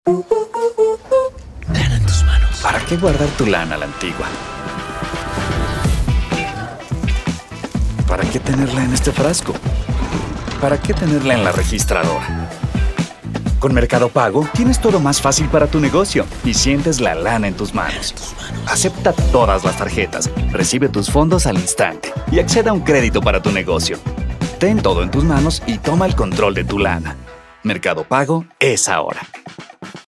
MercadoPago_TVAD
Neutral Spanish / Mexican / Latin American English /